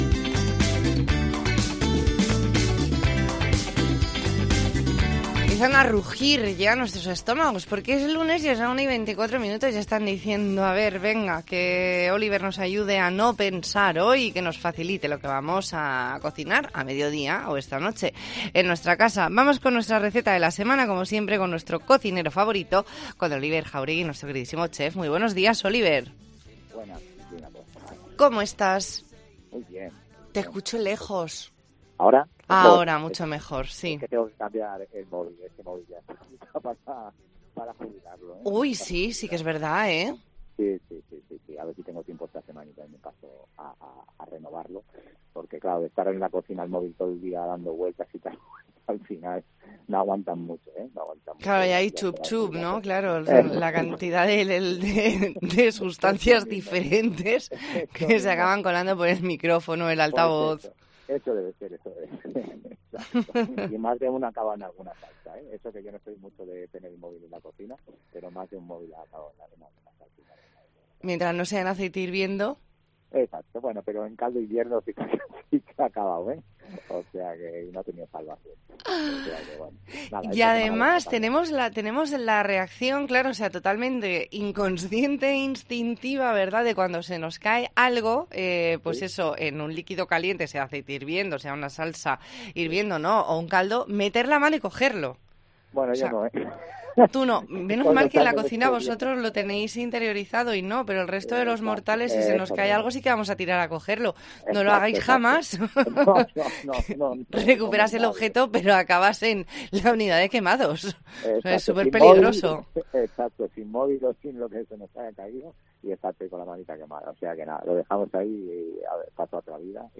Entrevista en La Mañana en COPE Más Mallorca, lunes 26 de septiembre de 2022.